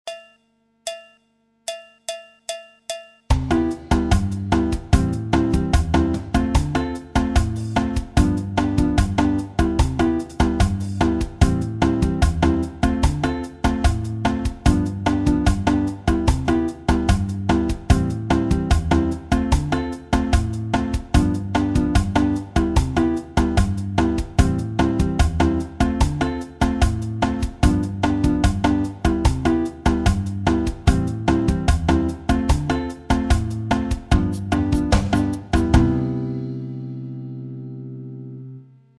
La guitare brésilienne et les rythmes brésiliens
J'ai mis un pattern de batterie avec clave samba et tambourim 1 samba.
La samba à la manière de João Bosco le roi du partido alto.